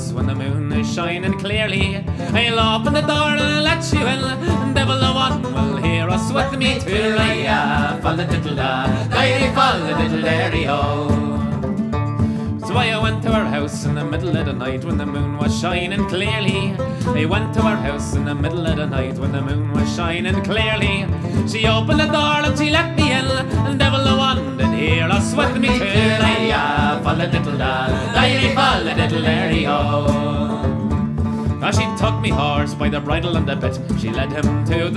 Жанр: Альтернатива / Фолк